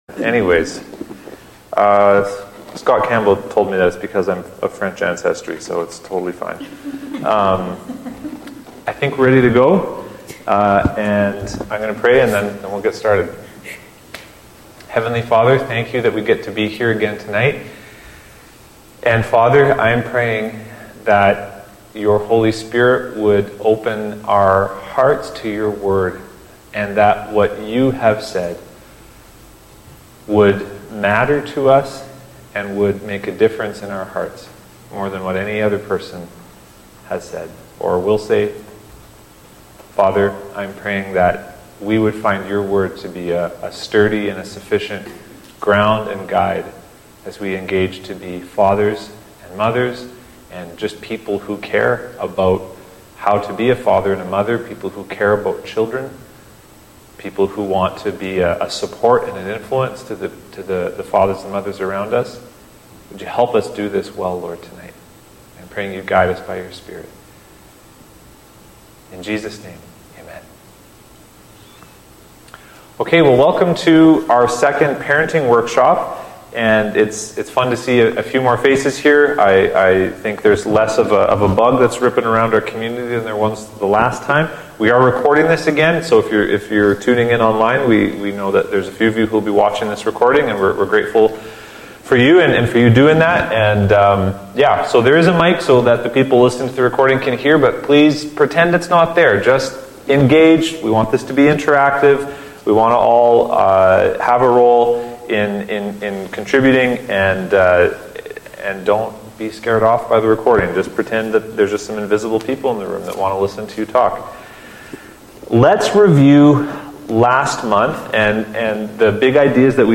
Parenting Workshop #2